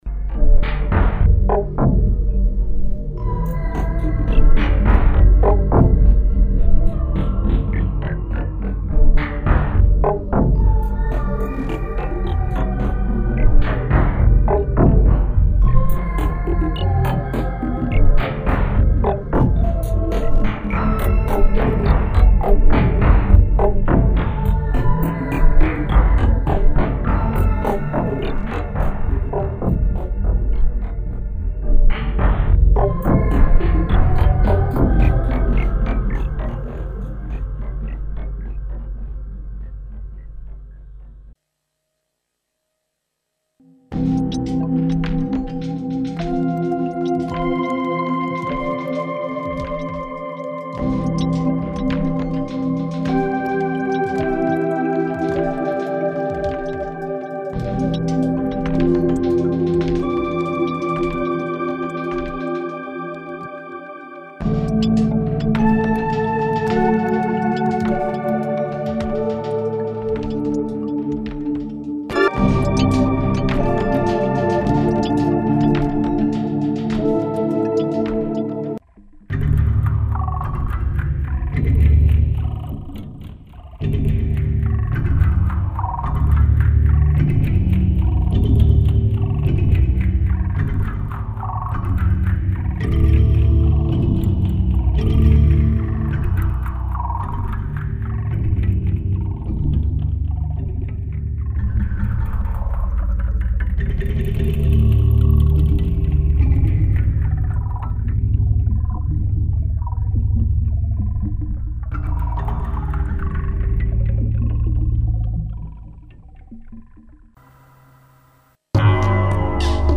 various sounds